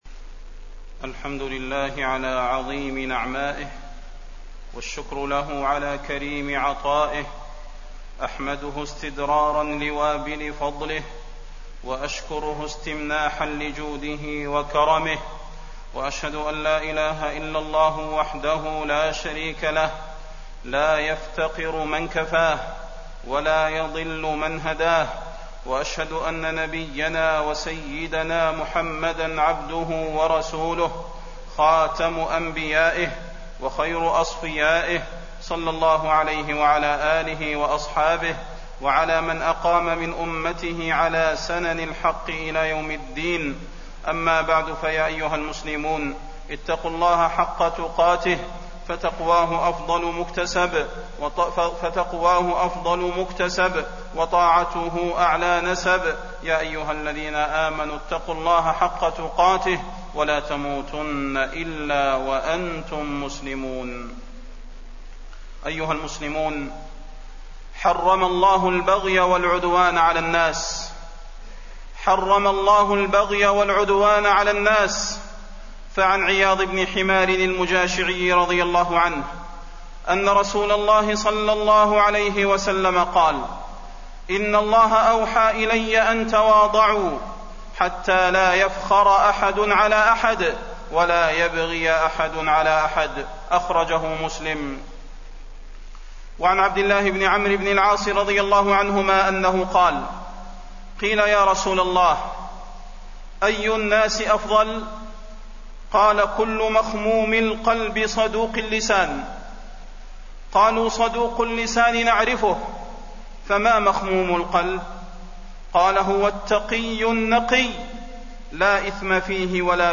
تاريخ النشر ١٤ صفر ١٤٣١ هـ المكان: المسجد النبوي الشيخ: فضيلة الشيخ د. صلاح بن محمد البدير فضيلة الشيخ د. صلاح بن محمد البدير رسالة إلى الجنود المرابطين على الثغور